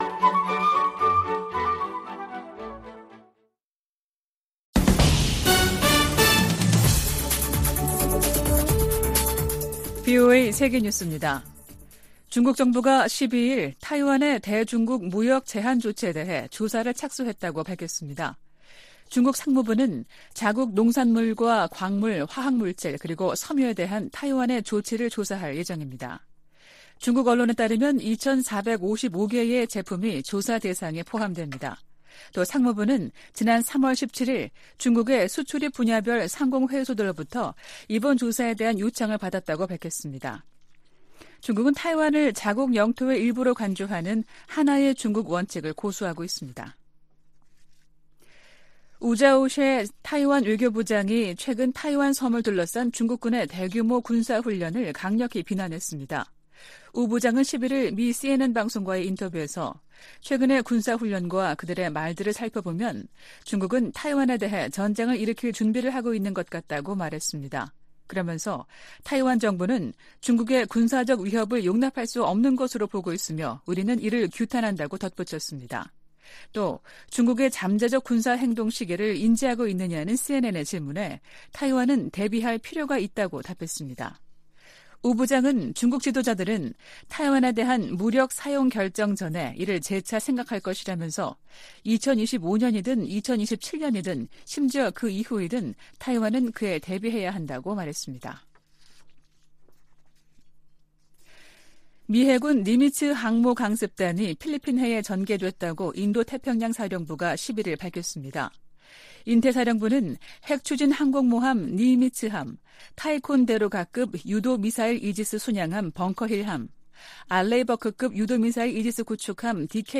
VOA 한국어 아침 뉴스 프로그램 '워싱턴 뉴스 광장' 2023년 4월 13일 방송입니다. 백악관은 최근 기밀 문건 유출 사건 직후 즉각적인 조치를 취했으며, 법무부 차원의 수사도 개시됐다고 확인했습니다. 미 국방장관과 국무장관은 기밀 유출 사건을 심각하게 받아들이며 철저히 조사하고 있다고 밝혔습니다. 미국은 대북 감시에 정찰 자산을 총동원하고 있으며, 미한일 3국 협력이 시너지를 낼 것이라고 미국 전문가들이 전했습니다.